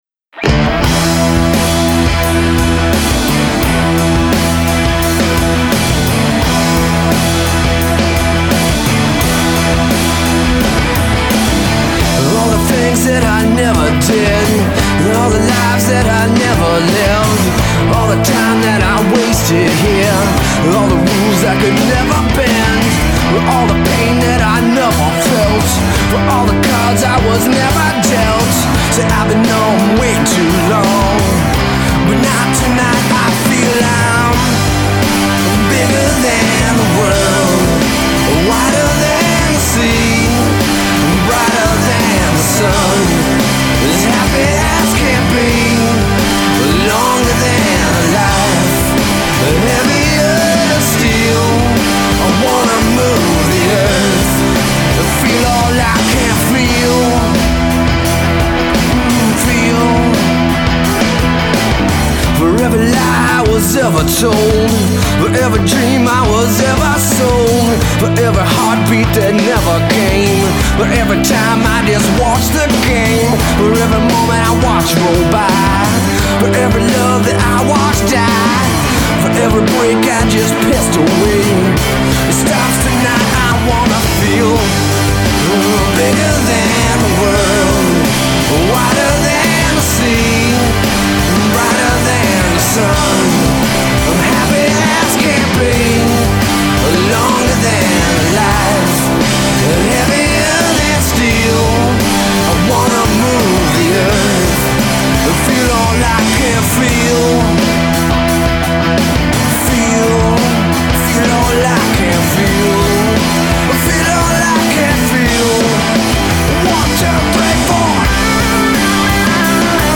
Up Tempo Mlvx, Full band